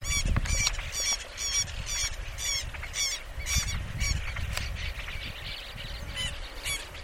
Agachona Chica (Thinocorus rumicivorus)
Ejemplar en vuelo, cuyo reclamo se escucha detrás del de Vanellus chilensis, encontrado en un rastrojo de girasol.
Partido de Saladillo, provincia de Buenos Aires.
Localización detallada: Campo cercano a la laguna La Cordobesa
Certeza: Observada, Vocalización Grabada